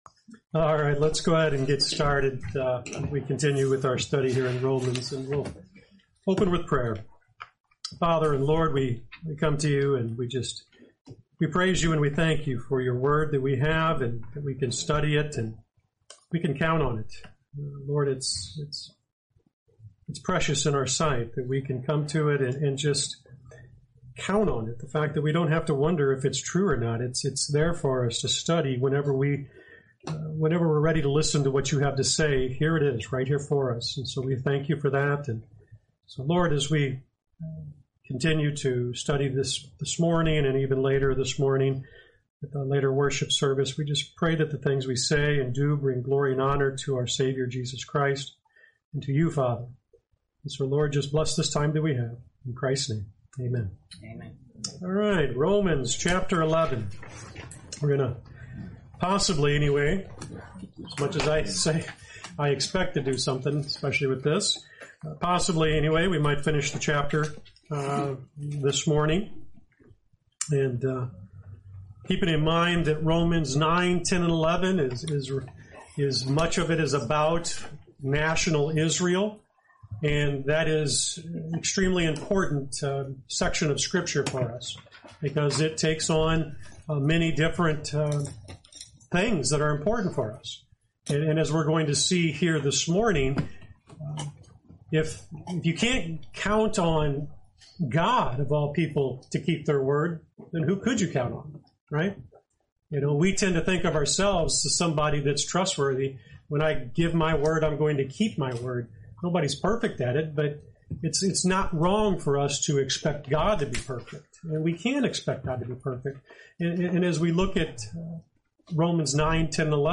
Lesson 74: Romans 11:29-36